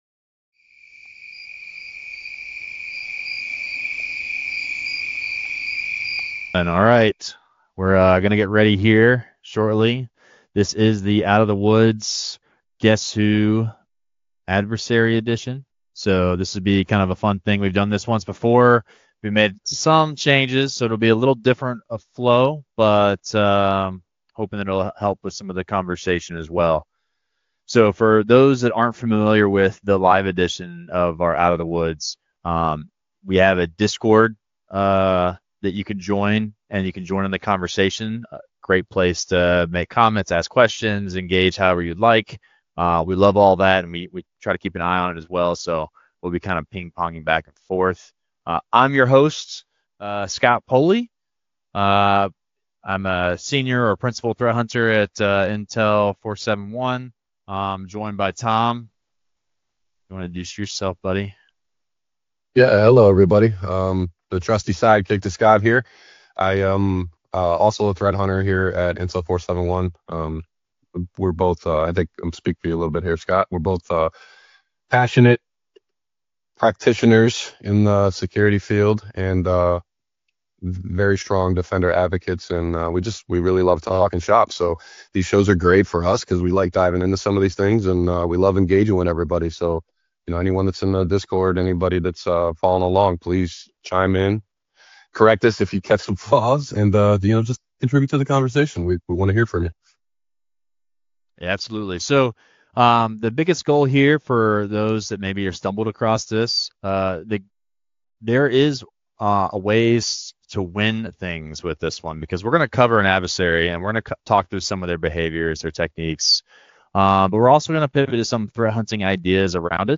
This live, interactive session will focus on a nation-state actor , revealing one phase of their campaign at a time as our hosts provide tradecraft clues and analysis.‌ Participants will examine how observed techniques align to MITRE ATT&CK, how vertical-specific targeting shapes operational decisions, and how behavioral patterns emerge across campaigns.